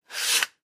in_sandpaper_stroke_03_hpx
Wood being sanded by hand. Tools, Hand Wood, Sanding Carpentry, Build